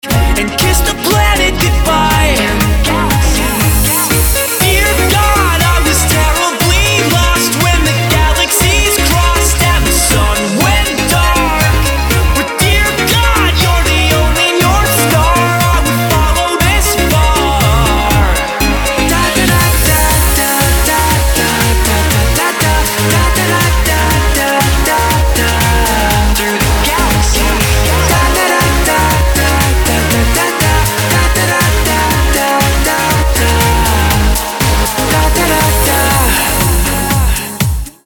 elektro-popová skupina